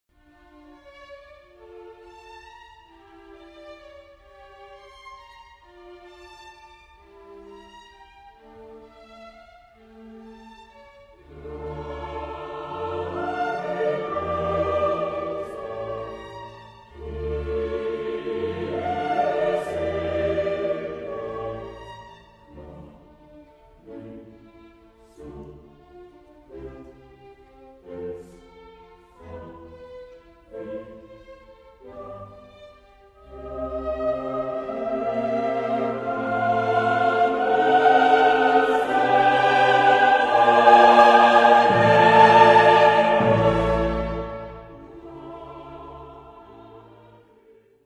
• In voorbeeld 9 is duidelijk sprake van 'melodie met begeleiding': de bovenstemmen (sopraan/eerste viool spelen een hoofdrol, de harmonie is daaraan ondergeschikt.
Ik heb dit voorbeeld hier op drie balken weergegeven om de eerste viool (die als enige instrument vanaf maat 3 niet een koorstem verdubbelt) goed te kunnen weergeven